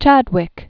(chădwĭk), Sir James 1891-1974.